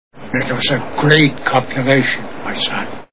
Little Big Man Movie Sound Bites
Chief Dan George as Lodge Skins: "It was a great copulation, my son."